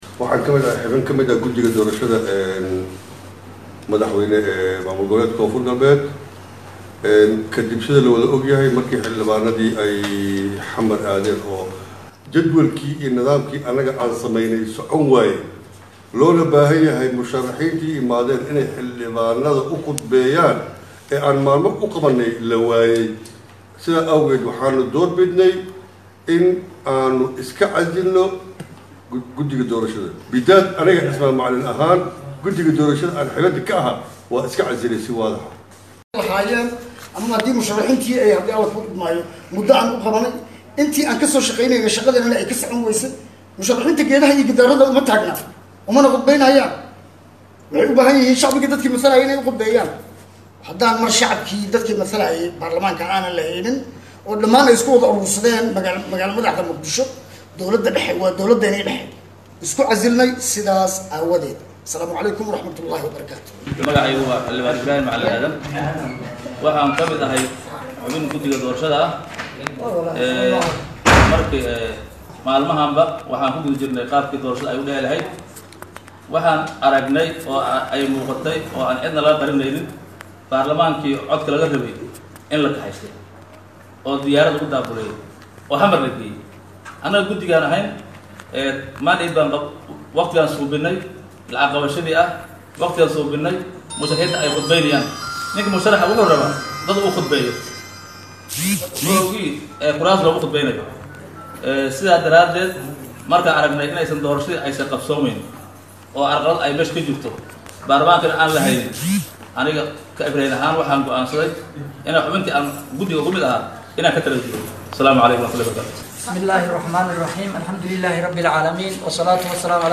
Hoos ka dhageyso guddiga oo ka hadlay sababtii ay isku casileen